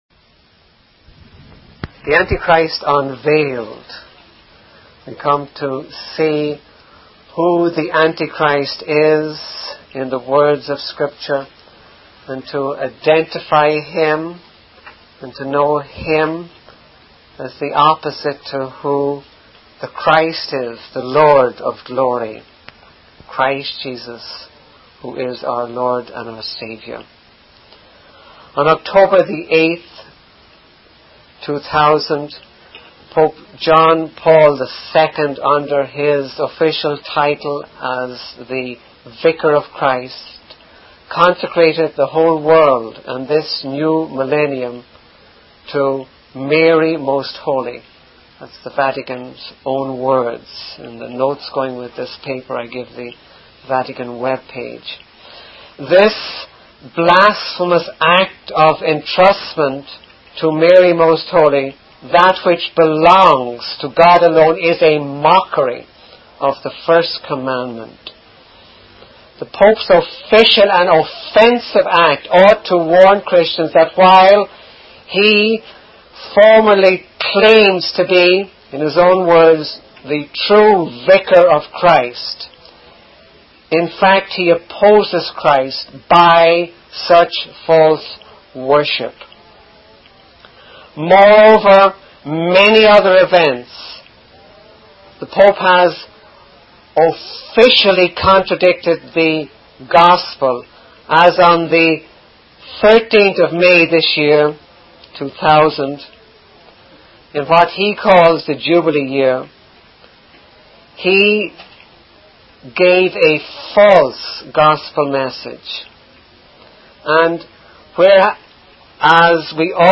In this sermon, the speaker emphasizes the message of having a personal relationship with God. He highlights the concept of God being right and without darkness, and the importance of walking in the light and having fellowship with one another.